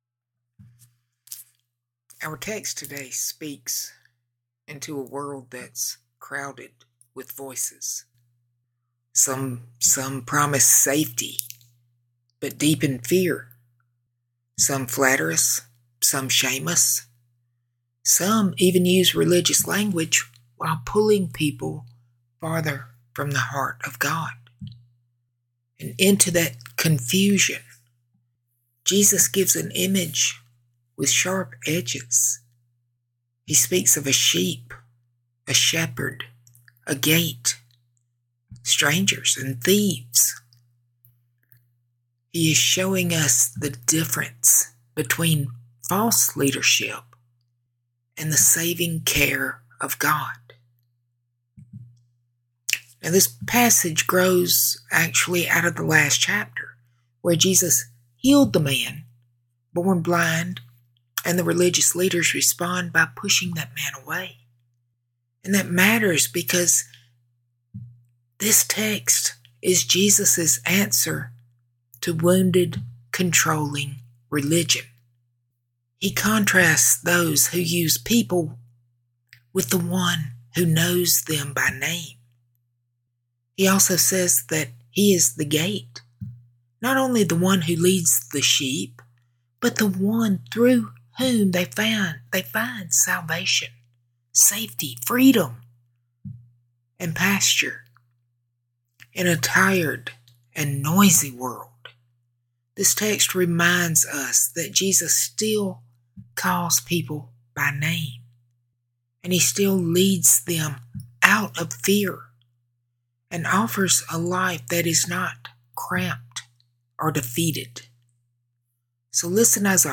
00:10 Direct Link to sermon Sermon Handout Recent Sermons The Stranger Who Was Jesus He is Not Here The King Who Comes Gently Come Out Opening Our Eyes